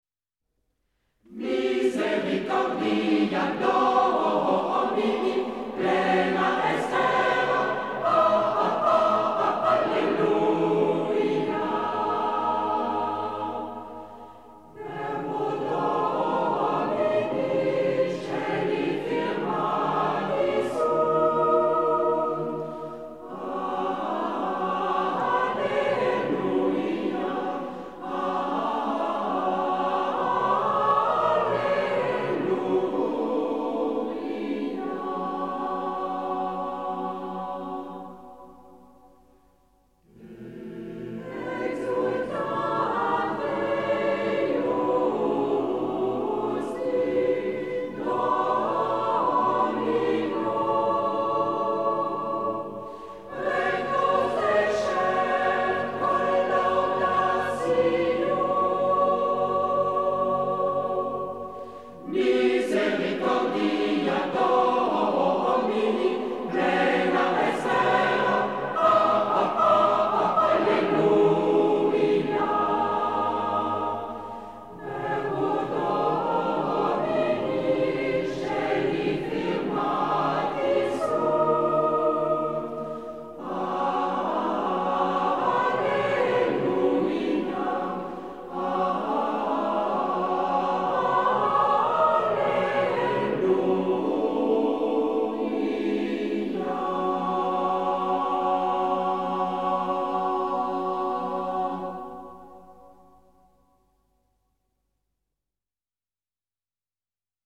Motet